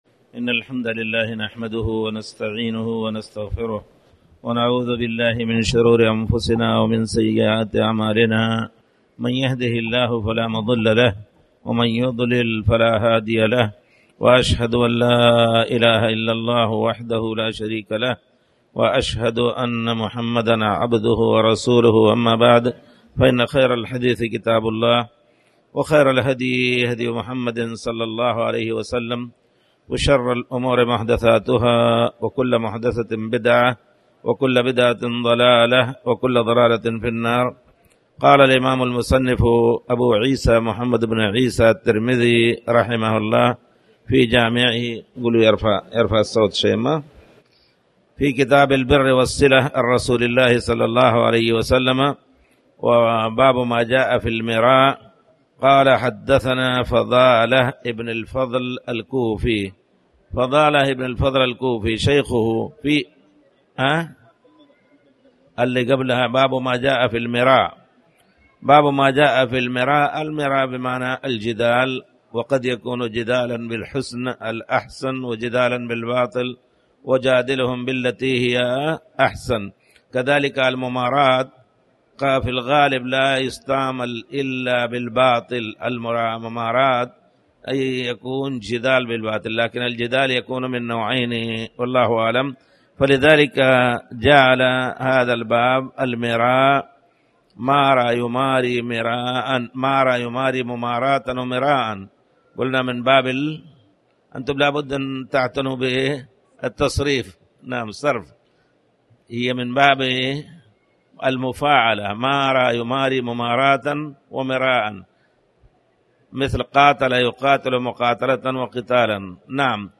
تاريخ النشر ٣ محرم ١٤٣٩ هـ المكان: المسجد الحرام الشيخ